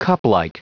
Prononciation du mot cuplike en anglais (fichier audio)
Prononciation du mot : cuplike